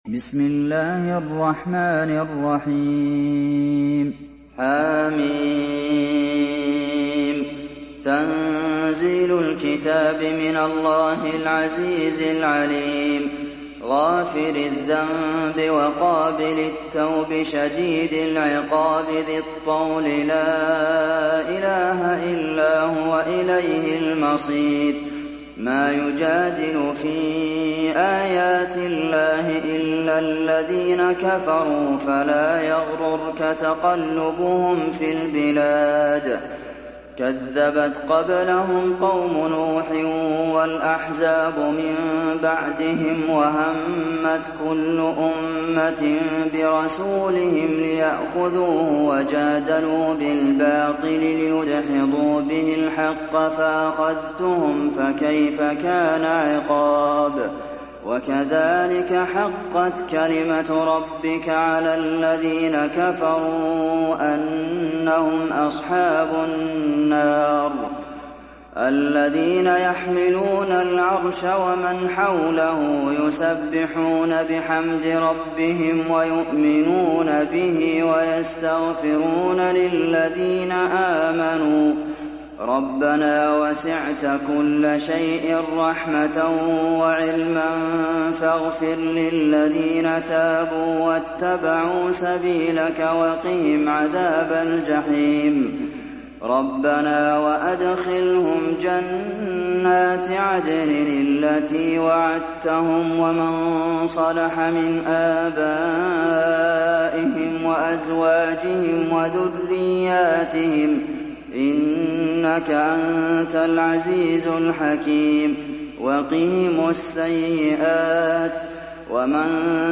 Sourate Ghafir Télécharger mp3 Abdulmohsen Al Qasim Riwayat Hafs an Assim, Téléchargez le Coran et écoutez les liens directs complets mp3